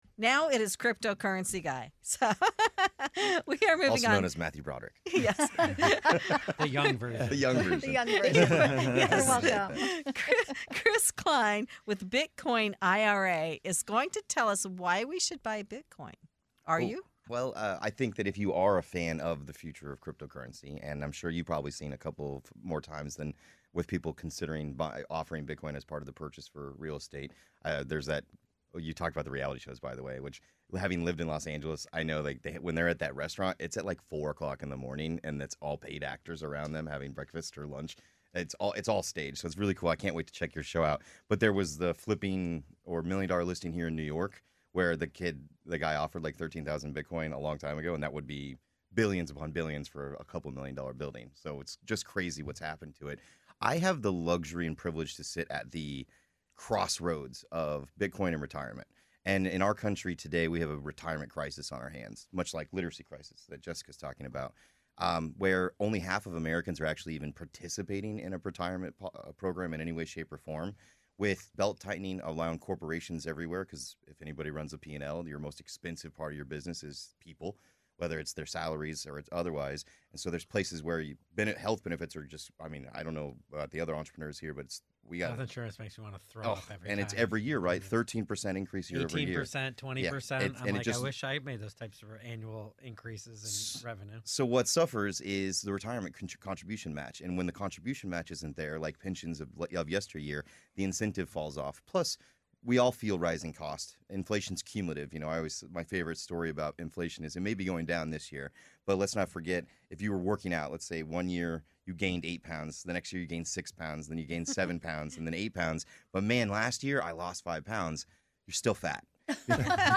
Bitcoin, cryptocurrency, and retirement planning collide in this eye-opening conversation